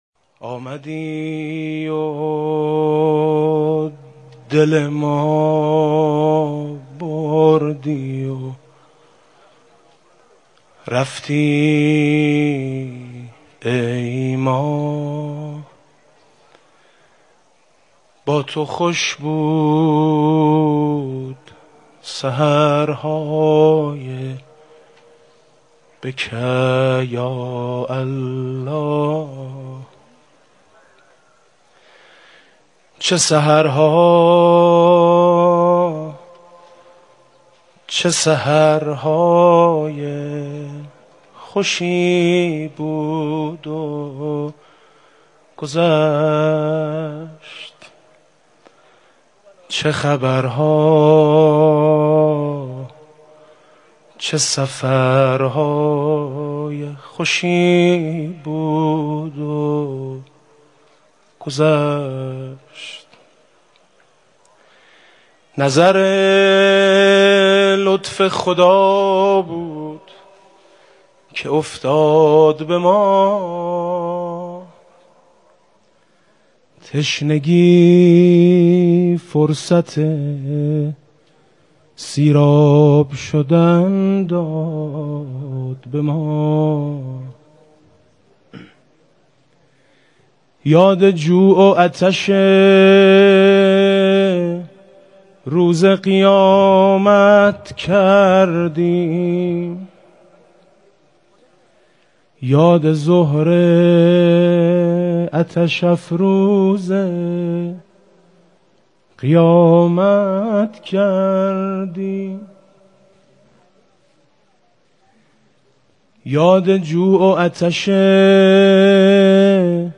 مراسم باشکوه نماز عید سعید فطر، با حضور اقشار مختلف مردم به امامت ولی امر مسلمین در مصلای امام خمینی تهران برگزار شد. در ابتدای این مراسم و پیش از اقامه‌ی نماز، حاج میثم مطیعی مداح اهل‌بیت (علیهم‌السلام) اشعاری را در وصف ماه مبارک رمضان و دیگر مسائل اخلاقی و سیاسی قرائت کرد.